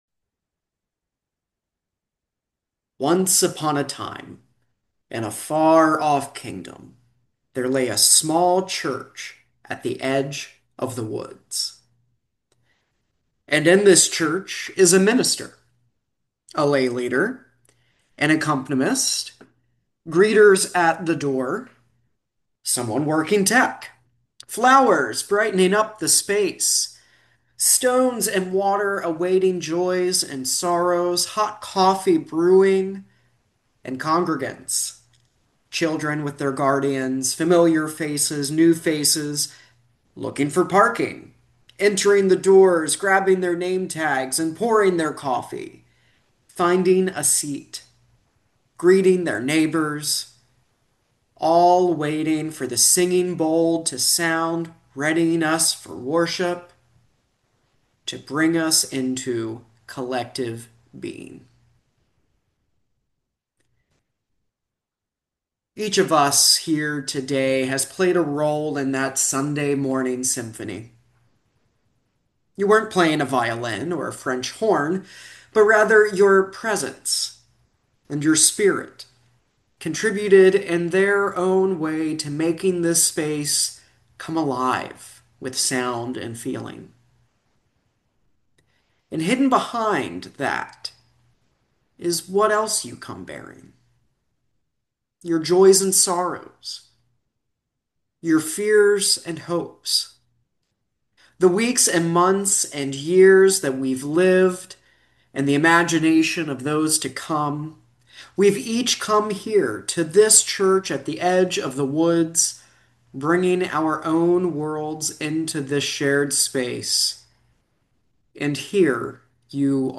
This sermon draws a poignant parallel between a Unitarian Universalist congregation and the characters in the musical Into the Woods to illustrate the necessity of communal support.